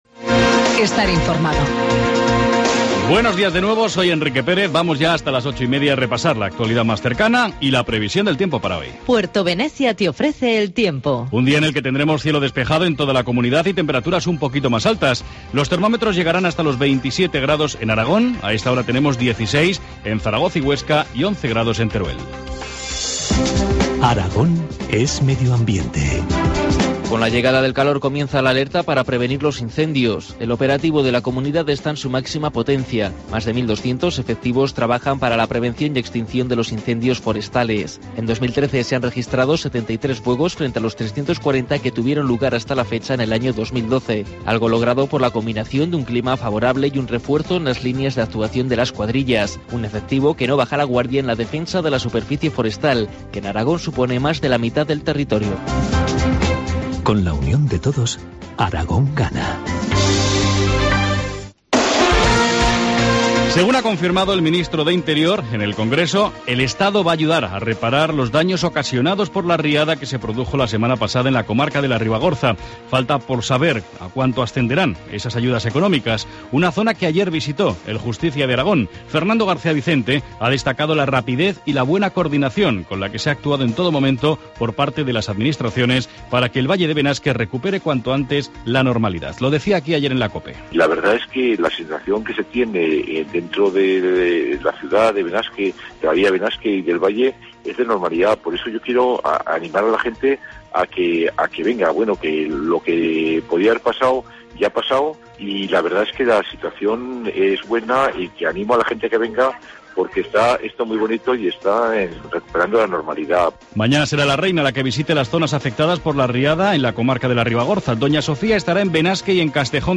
Informativo matinal, jueves 27 de junio, 8.25 horas